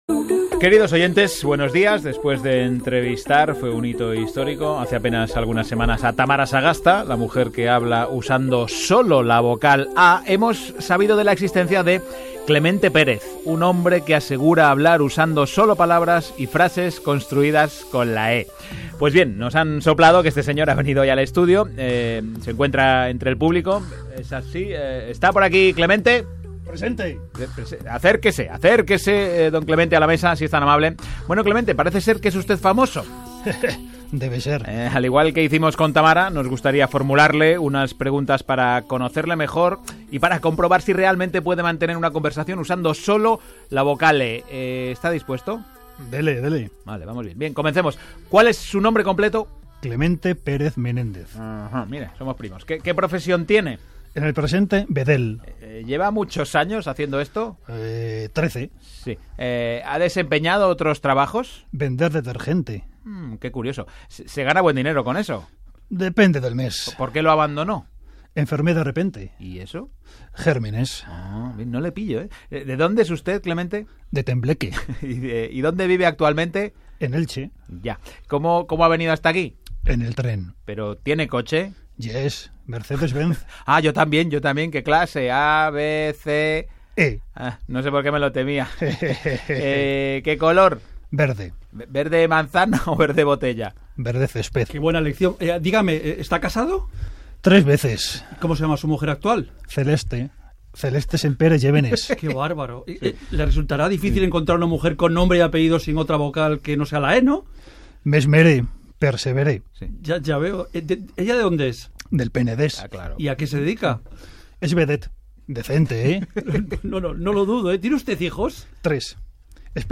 Esta semana, el equipo del programa Las Mañanas de Radio Nacional de España ha puesto de nuevo voz a otro de mis textos.